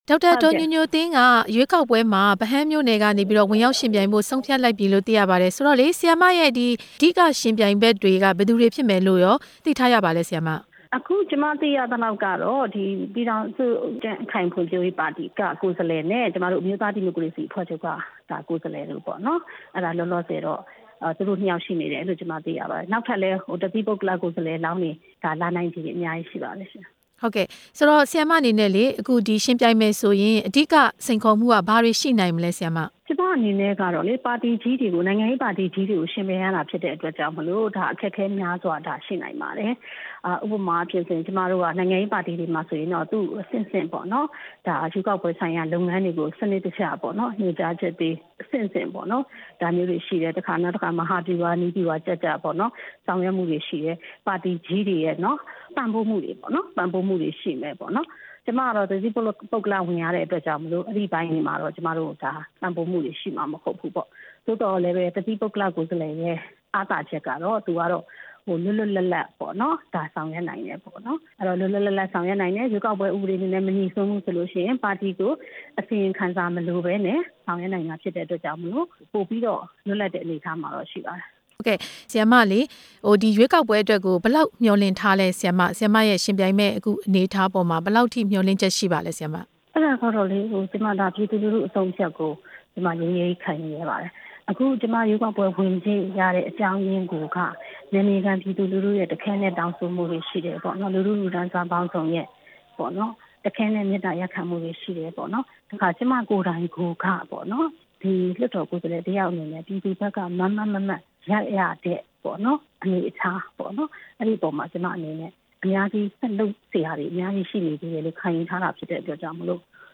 ဒေါက်တာ ညိုညိုသင်းနဲ့ မေးမြန်းချက်